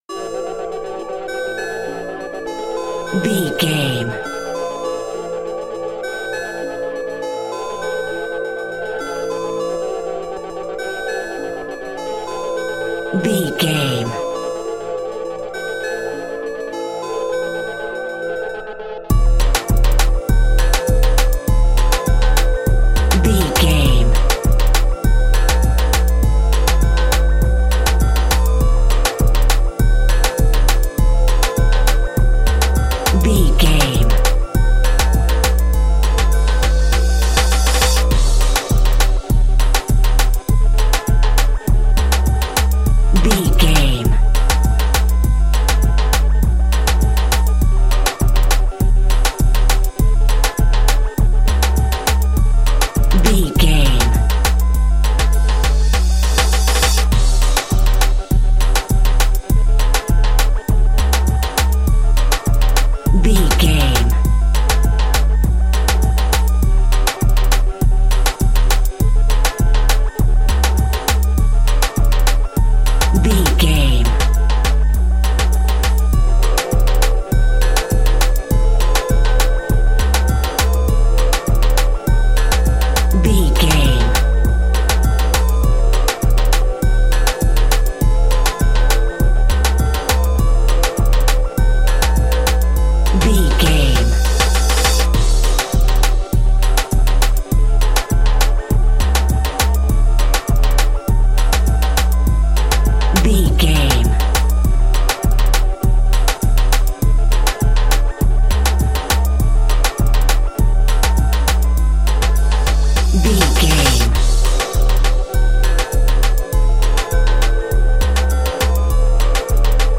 Classic reggae music with that skank bounce reggae feeling.
Aeolian/Minor
D♭
dub
laid back
chilled
off beat
drums
skank guitar
hammond organ
transistor guitar
percussion
horns